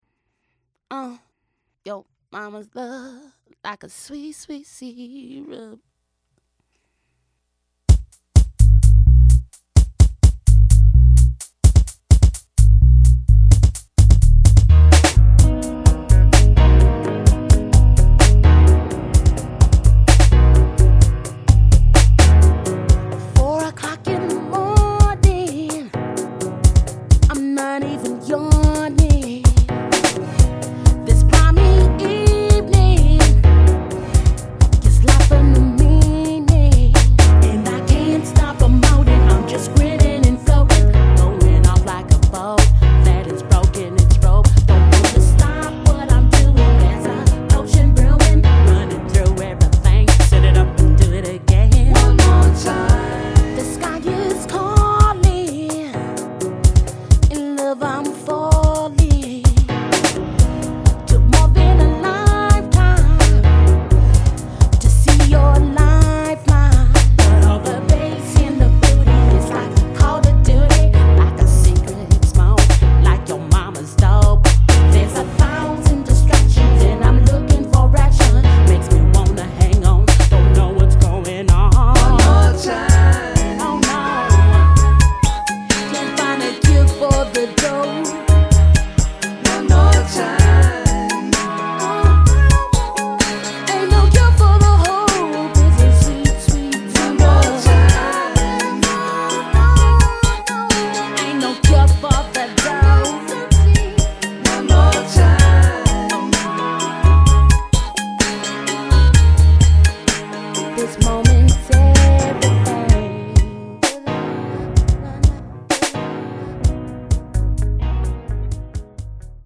R&B CD project